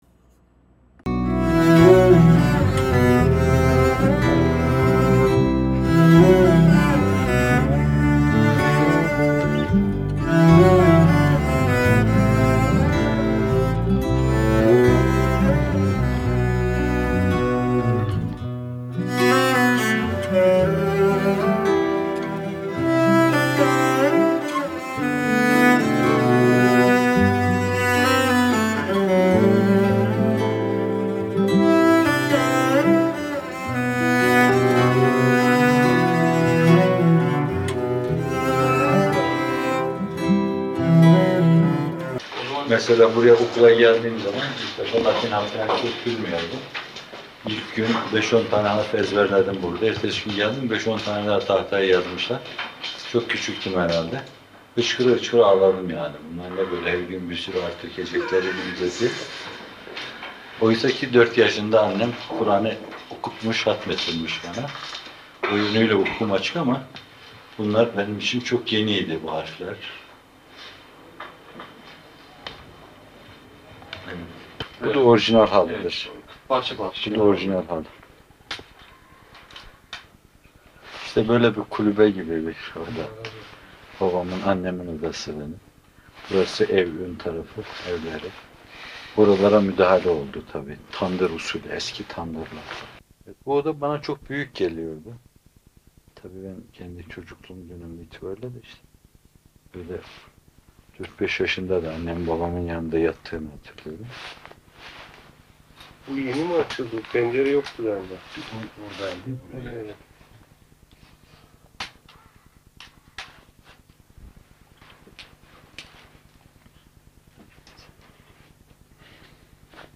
Muhterem Fethullah Gülen Hocaefendi’nin (rahmetullahi aleyh), 1995 yılında, beraberindeki bir grup ağabey ile doğup büyüdüğü toprakları ziyaretine dair görüntüler.